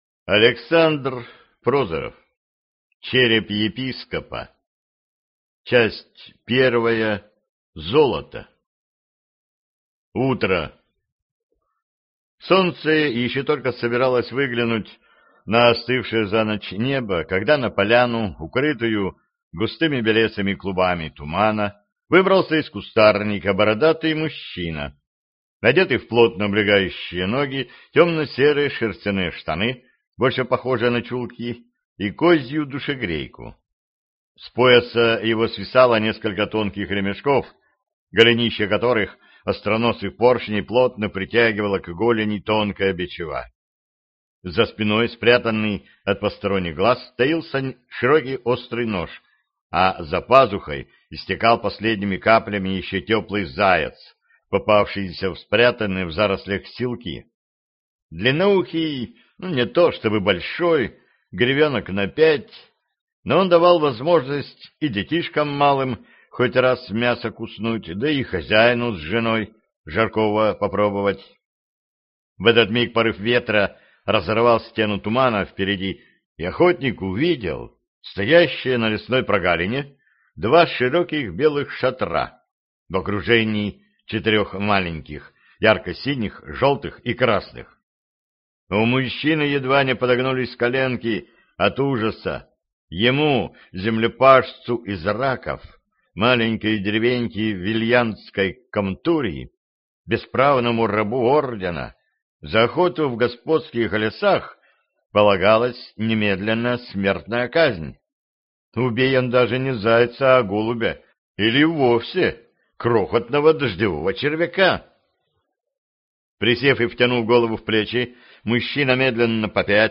Аудиокнига Череп епископа | Библиотека аудиокниг